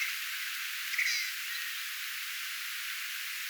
pikkukoskelon soidinääni
pikkukoskelon_yksi_soidinaani.mp3